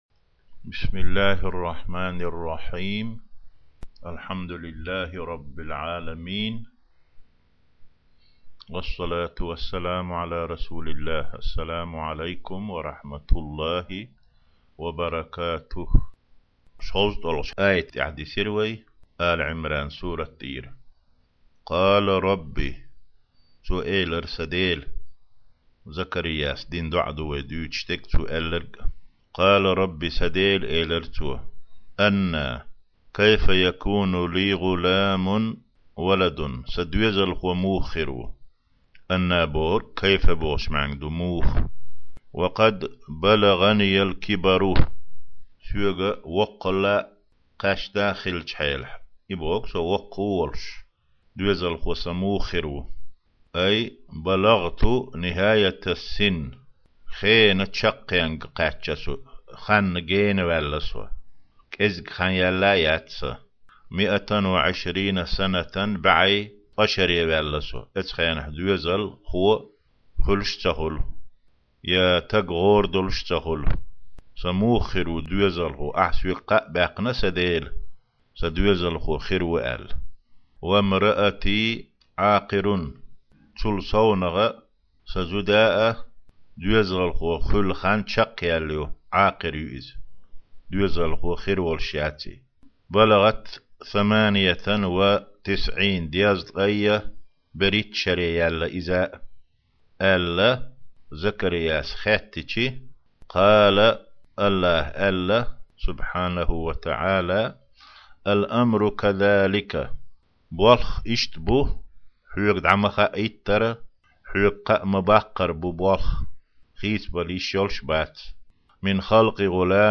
6-гIа дарс: Сурат Áли-Iимран 40-41 аят (Тафсирул-Жалалайн).